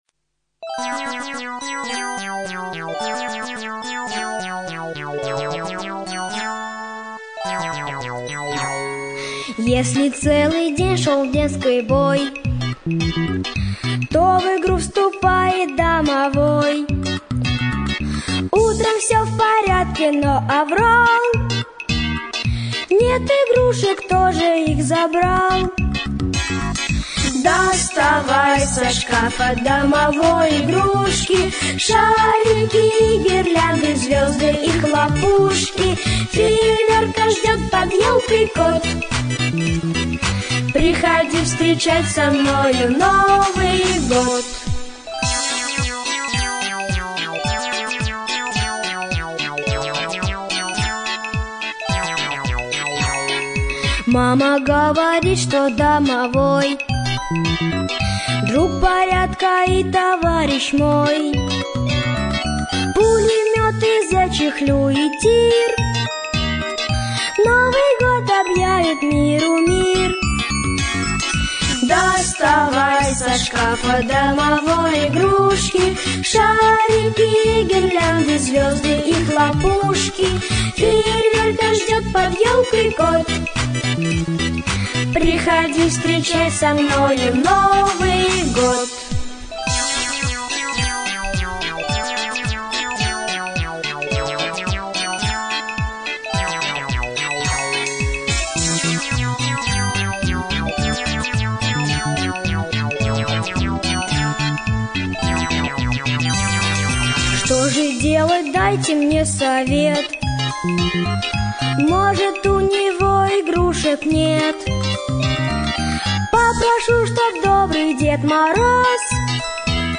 🎶 Детские песни / Песни на Новый год 🎄